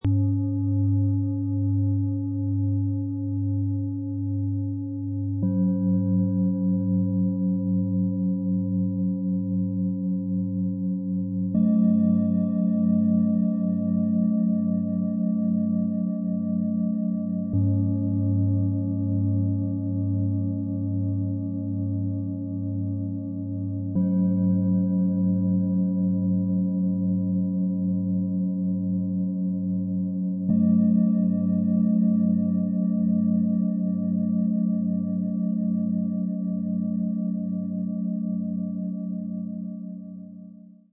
Im Jetzt - Erdung, Ausgleich, Licht - Set aus 3 Klangschalen, für Meditation & sanfte Klangarbeit mit Kindern und Jugendlichen Ø 16,3 - 21,9 cm, 2,34 kg
Tiefer, tragender Ton mit beruhigender und zentrierender Schwingung.
Freundlicher, weicher Ton mit ausgleichender, harmonischer Vibration.
Heller Klang mit feiner, schneller Schwingung.
Die fein abgestimmten Töne wirken beruhigend und erhebend zugleich – besonders wohltuend in der Klangmeditation, bei Klangreisen und in der Klangmassage mit Kindern und Jugendlichen.
Die Aufnahme zeigt die natürliche Abstimmung: von tiefer Erdung über zentrierte Mitte bis zu heller Öffnung.
MaterialBronze